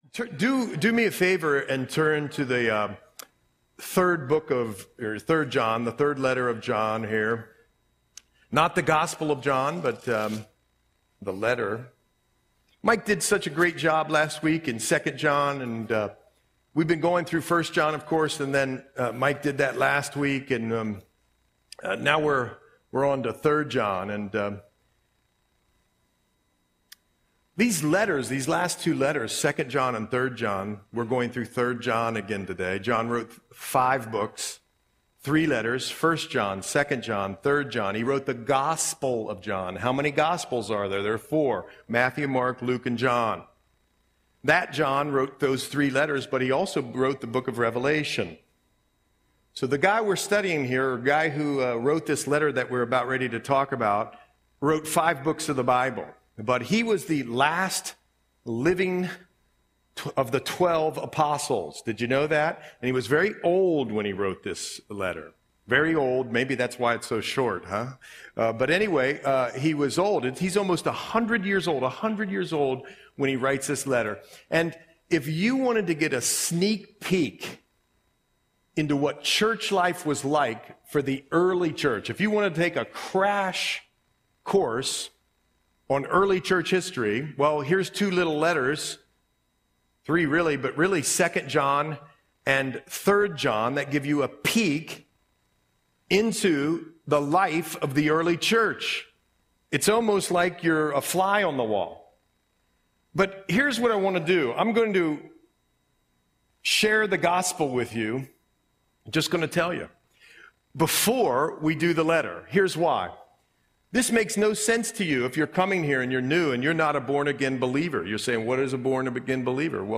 Audio Sermon - September 29, 2024